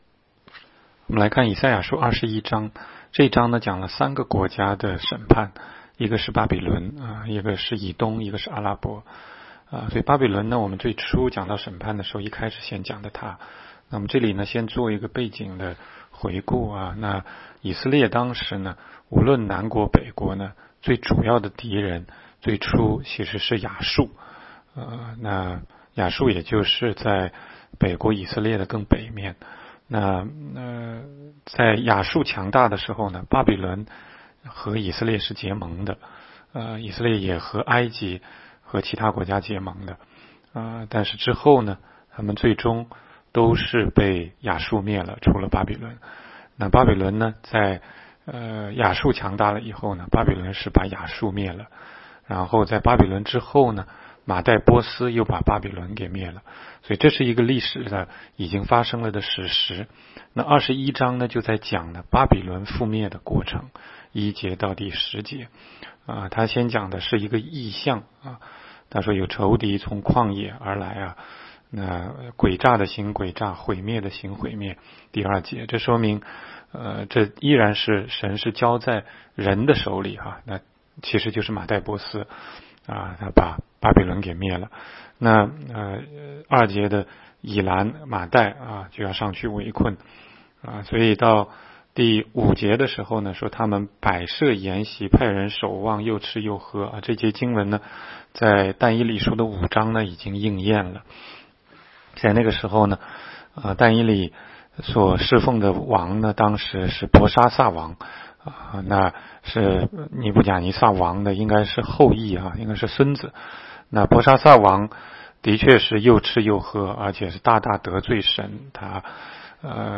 16街讲道录音 - 每日读经 -《 以赛亚书》21章